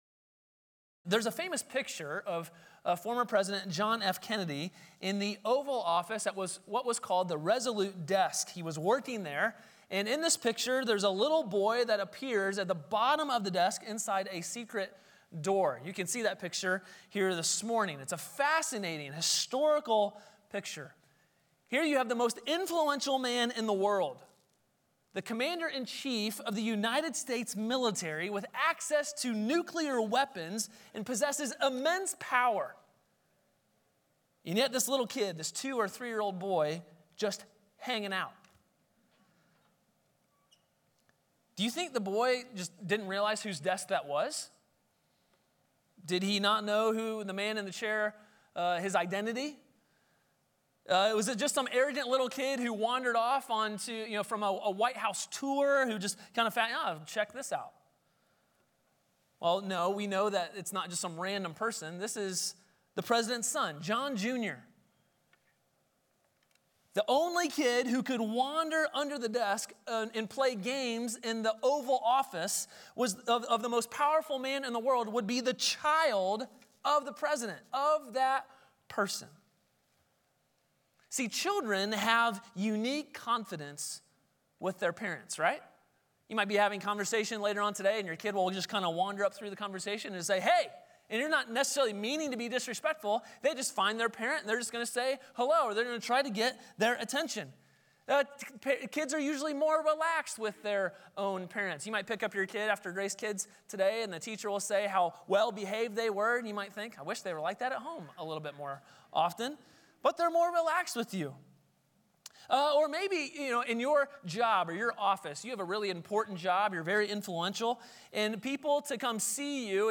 Sermons • Grace Polaris Church